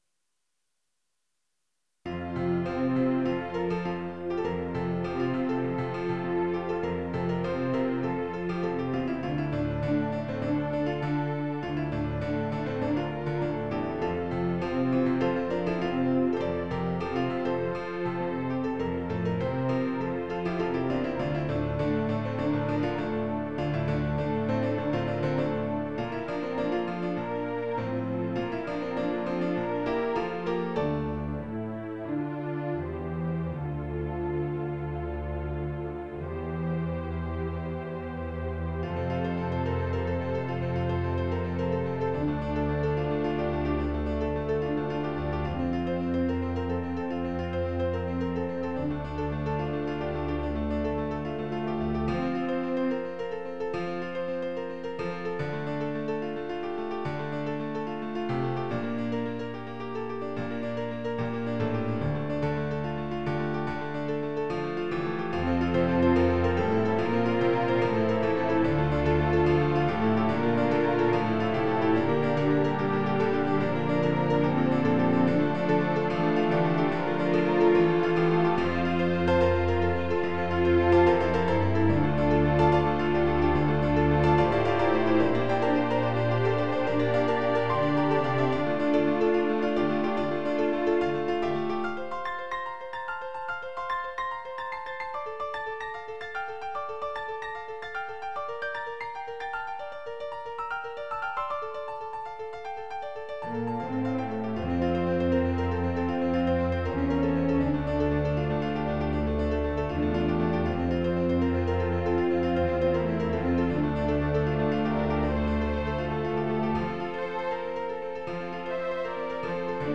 Quartet, Piano, Strings